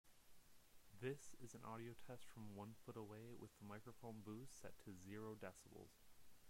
My Mic has background noise.
I just purchased a MXL 770 running into a InnoGear 1- Channel 48V Phantom Power Supply, that then feeds into my on board sound card via 3pin XLR Female to 1/4" 6.35mm Mono Male Adapter into a 1/8 inch Male to 1/4 inch Female Audio Jack Adapter.
It wasn't working at all on one of my computers so I tried on another with better success but I'm getting a lot of background noise, is this normal?